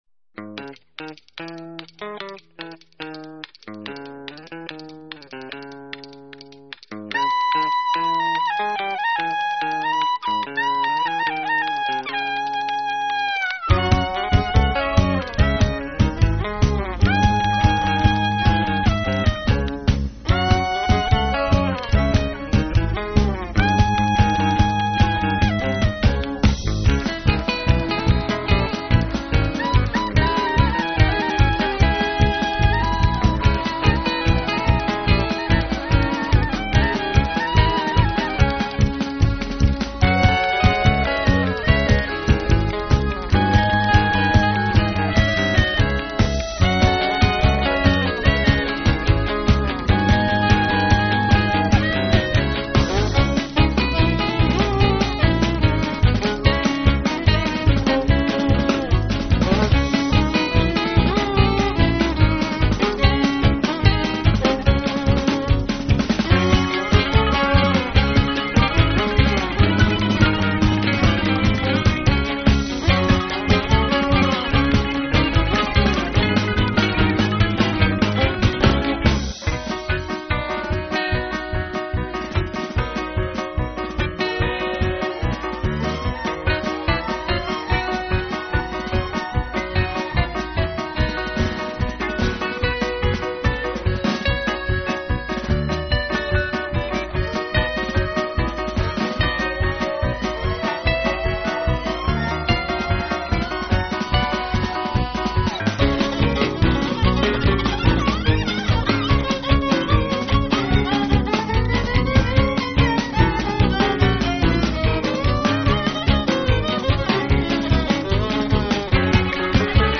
drums, percussions
percussions, congas, berimbau